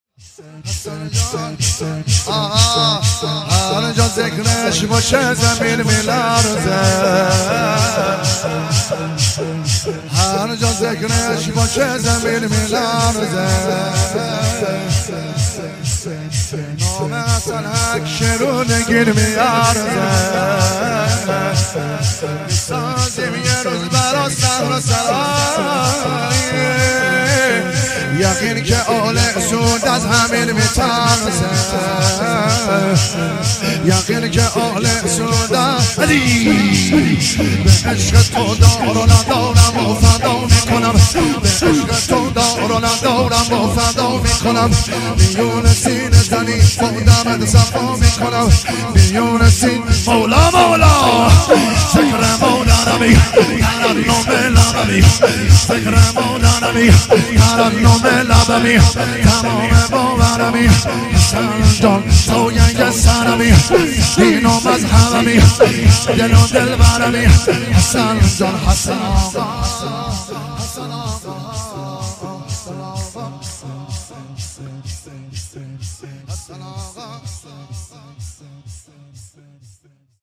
شور- هر جا ذکرش باشه
مجمع خیمه گاه عزاداران ساری
شب ششم محرم 98